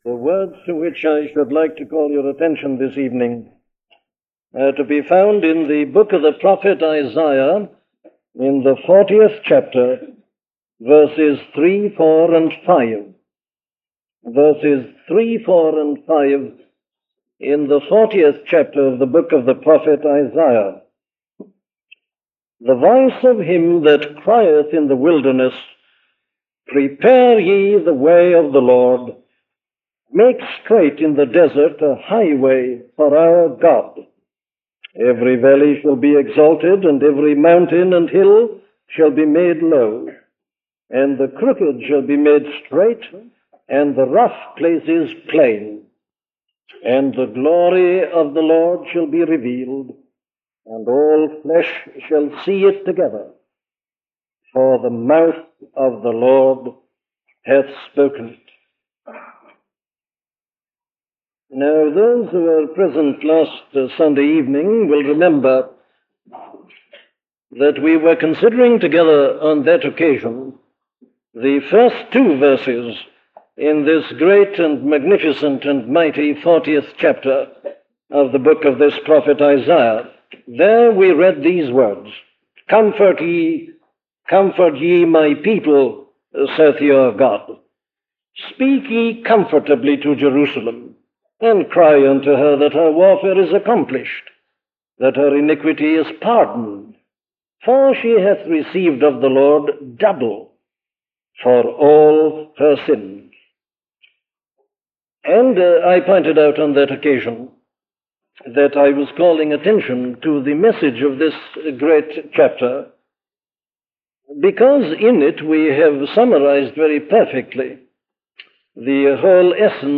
What is Christianity? - a sermon from Dr. Martyn Lloyd Jones